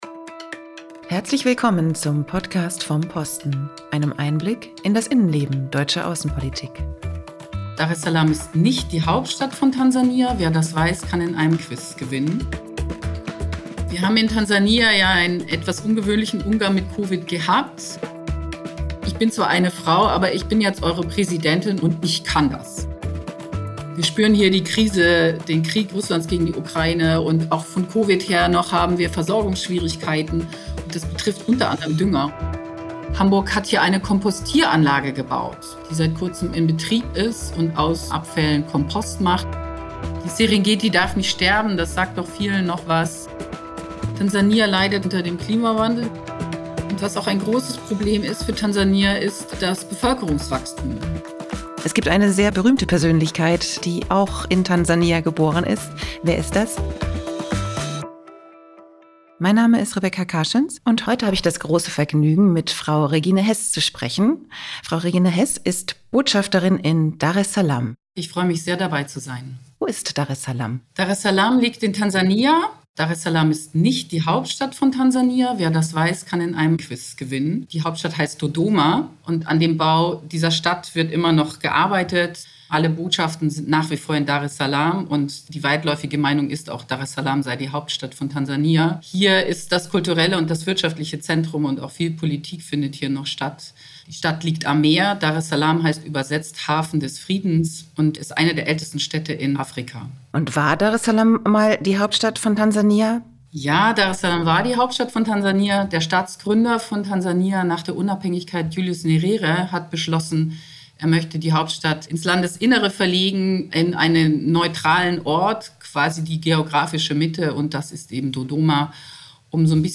spricht in dieser Folge des Podcasts mit der deutschen Botschafterin in Daressalam, Regine Heß, über ihre Arbeit in dem ostafrikanischen Land. Es geht um die gemeinsame Vergangenheit von Tansania und Deutschland, aber auch um Initiativen und Perspektiven für eine gemeinsame Zukunft.